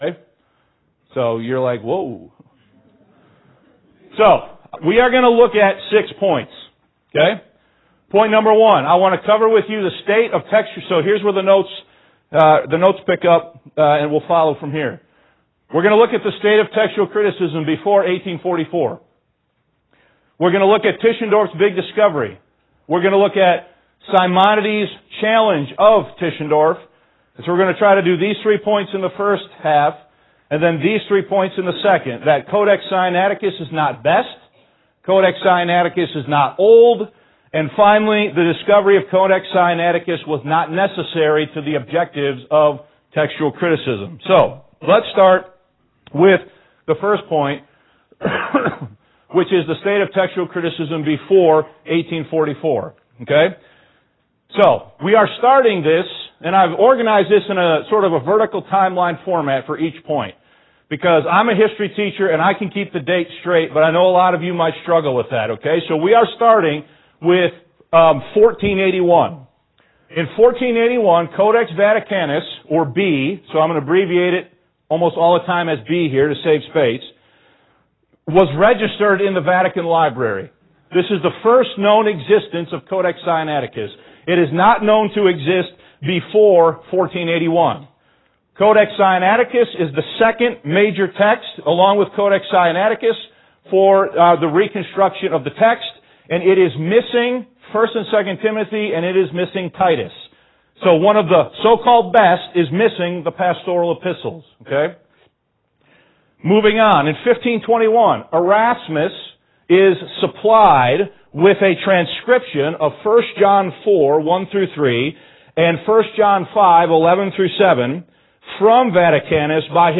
This was a two-part study taught at Shorewood Bible Church in Rolling Meadow, IL on April 28, 2018.